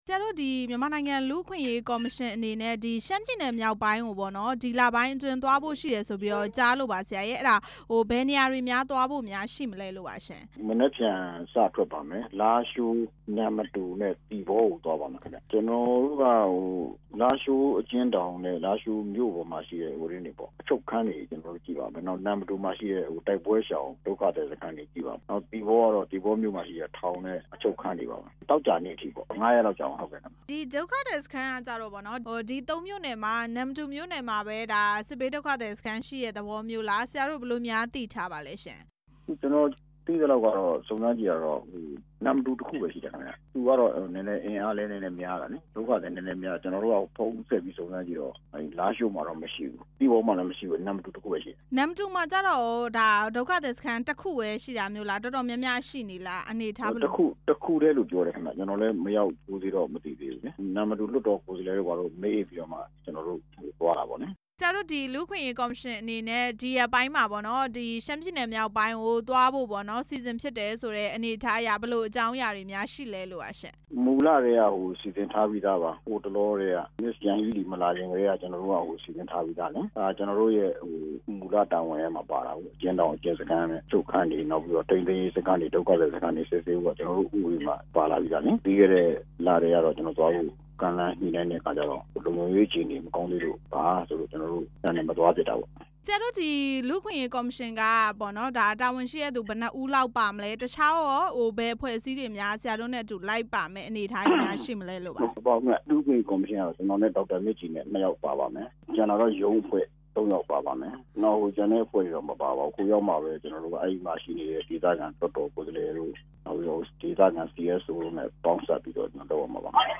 အမျိုးသား လူ့အခွင့်အရေးကော်မရှင် အဖွဲ့ဝင် ဦးယုလွင်အောင် နဲ့ မေးမြန်းချက်